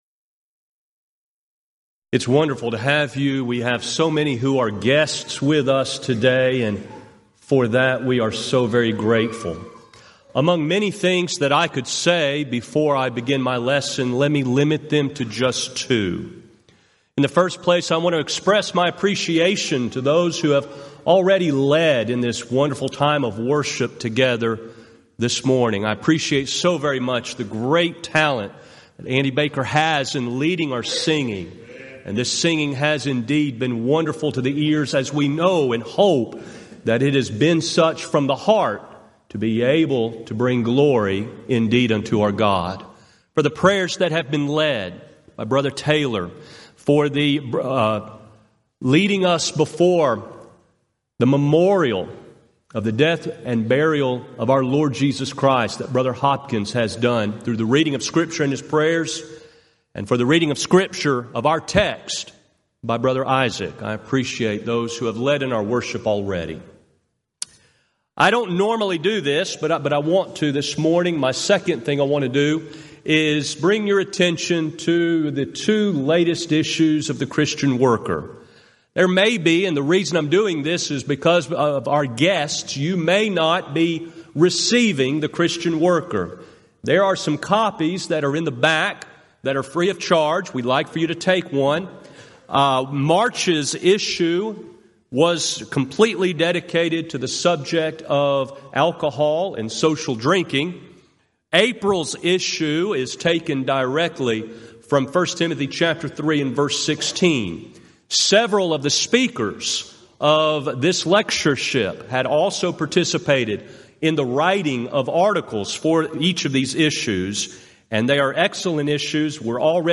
Event: 34th Annual Southwest Lectures Theme/Title: God's Help with Life's Struggles
lecture